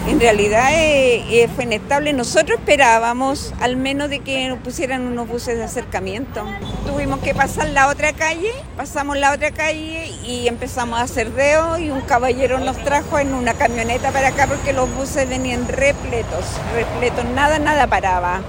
En conversación con Radio Bío Bío, una mujer que tenía una hora médica dijo que hizo dedo para poder llegar hasta un paradero más cercano.